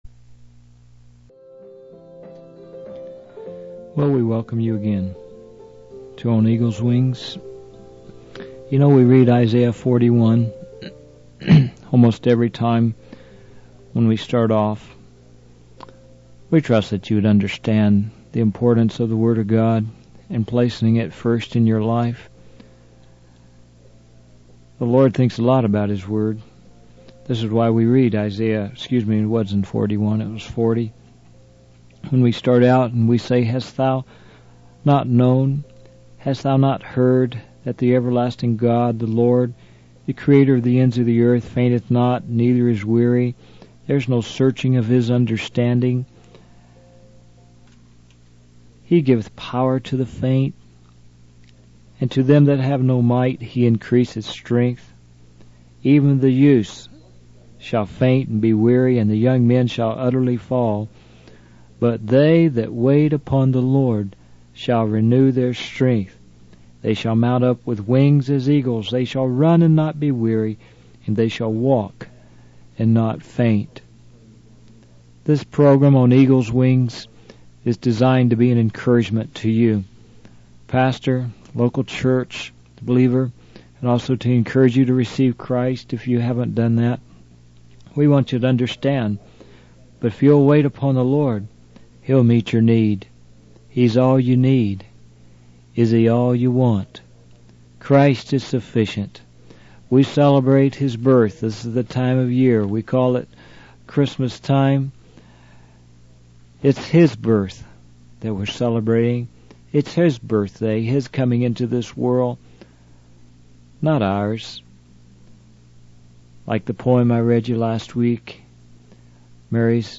In this sermon, the speaker emphasizes the need for churches to prioritize the presence of the Lord and reflect the holiness of God. He expresses concern about the entertainment and social aspects that can distract from the true purpose of the church.